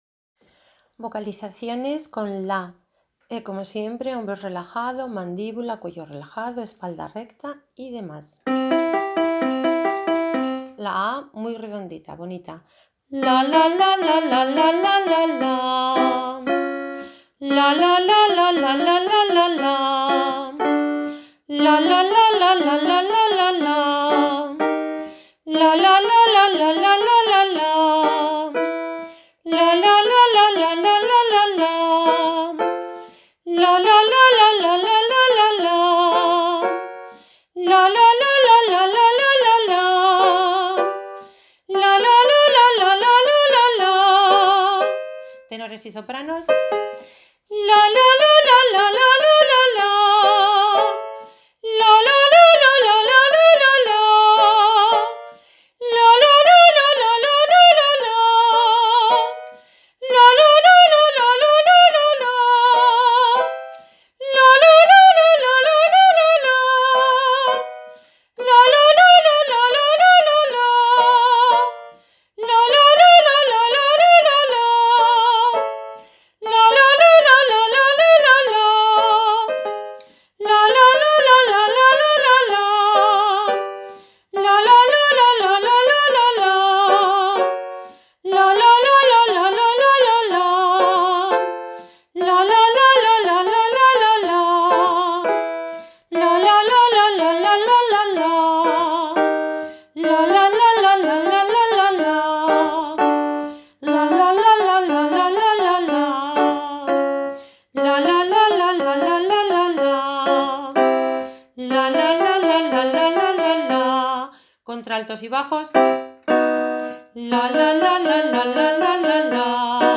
Canto coral